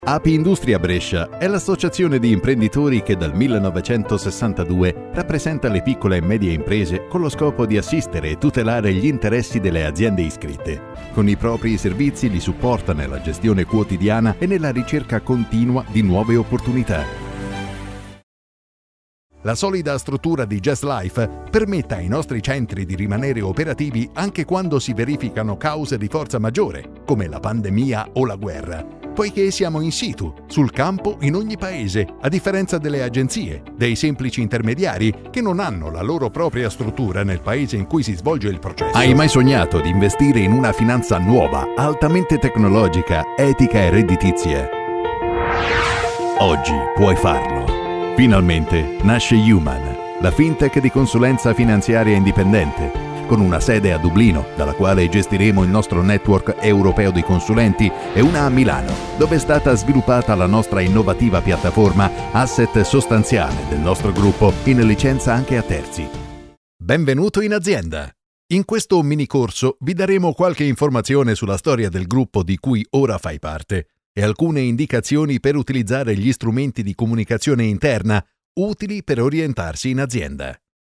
Corporate Videos
My voice is deep, mature, warm and enveloping, but also aggressive, emotional and relaxing.